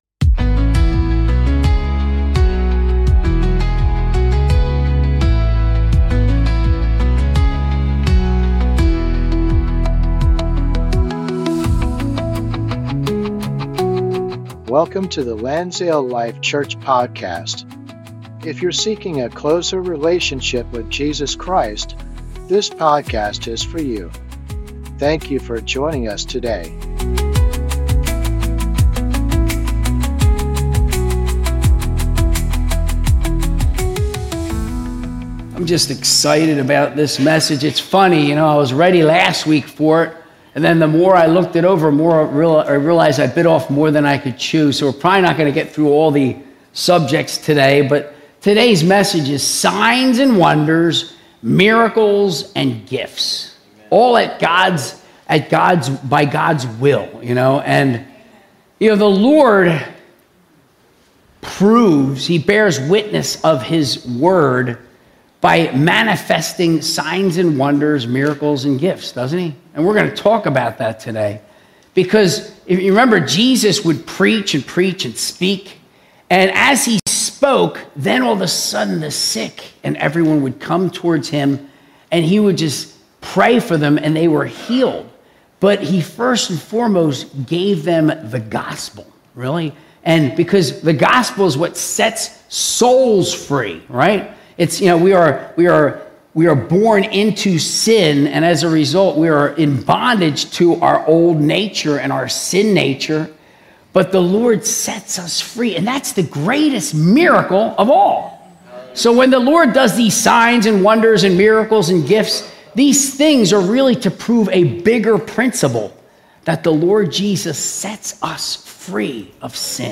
Sunday Service - 2025-06-22